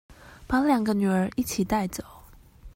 Голоса - Тайваньский 119